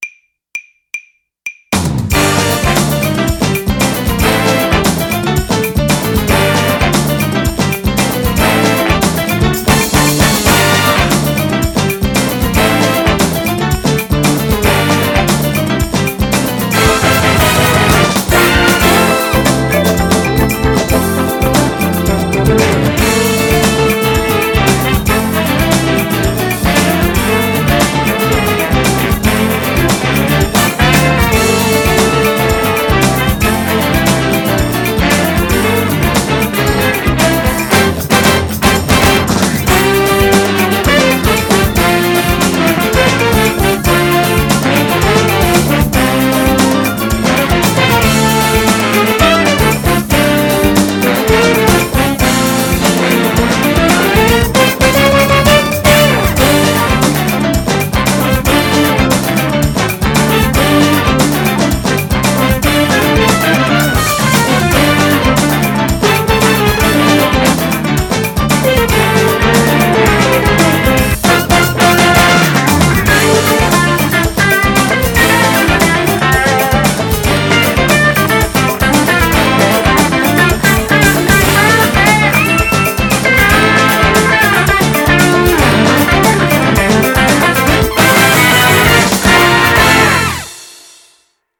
BPM115
Audio QualityPerfect (High Quality)
Another jazzy bop